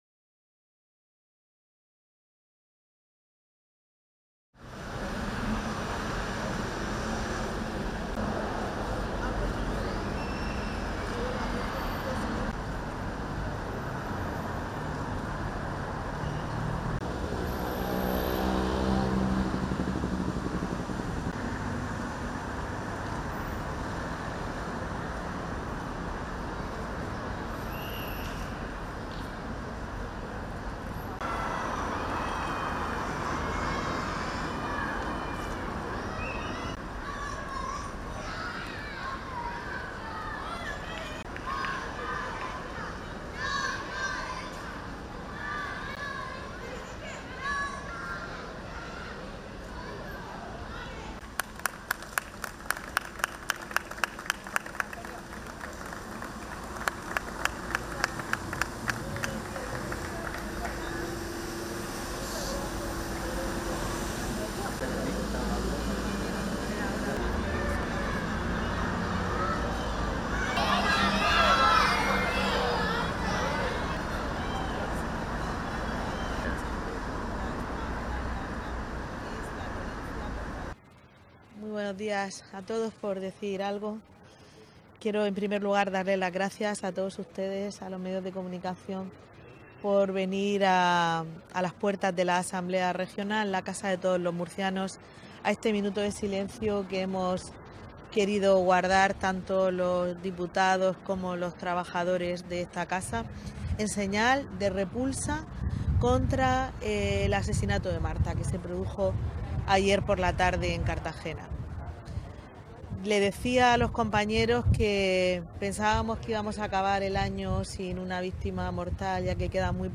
• Declaraciones de la presidenta de la Asamblea Regional, Visitación Martínez